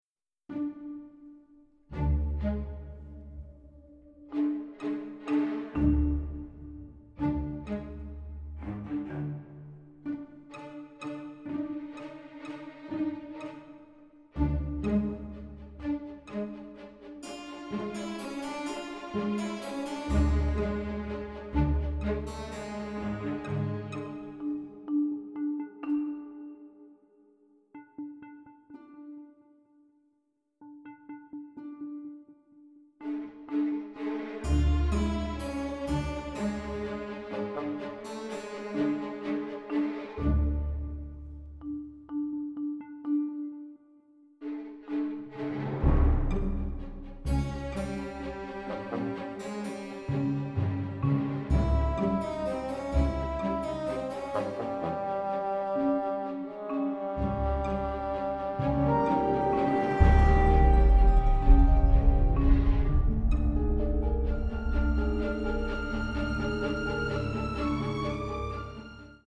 wit, slapstick, mystery and suspense with a classy touch